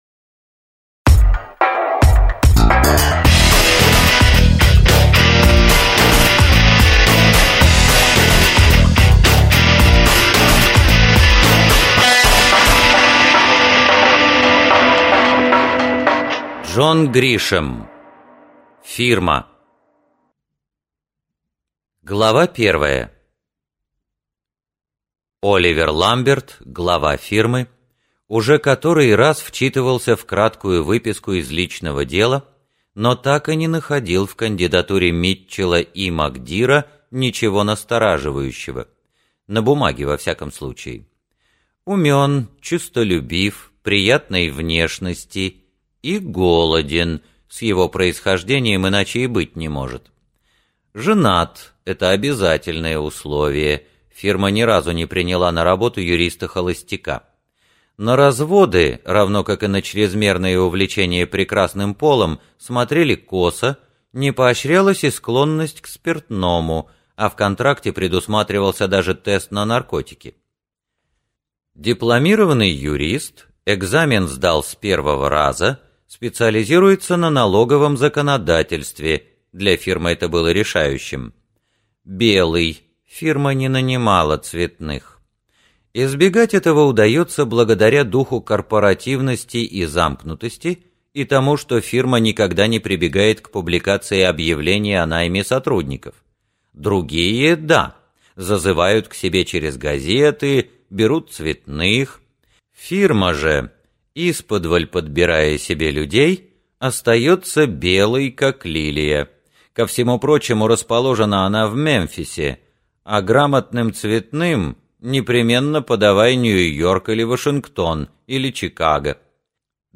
Аудиокнига Фирма - купить, скачать и слушать онлайн | КнигоПоиск